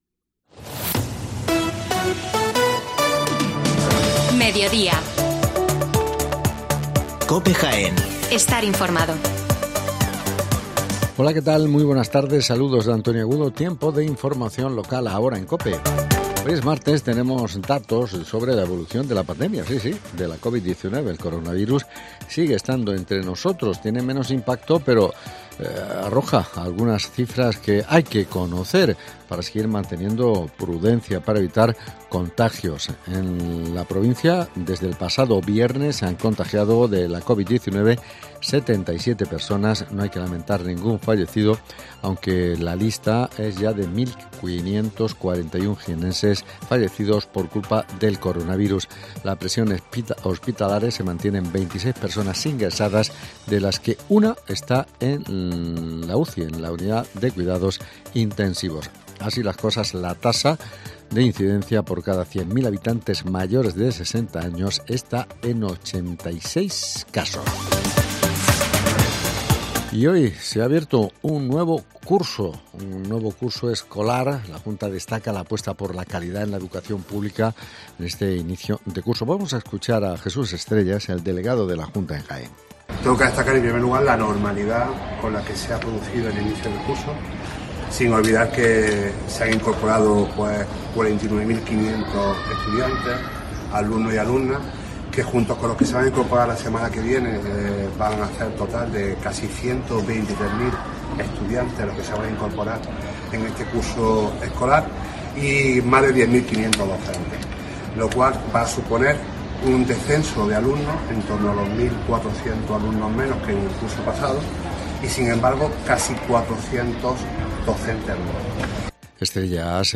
Las noticias localesm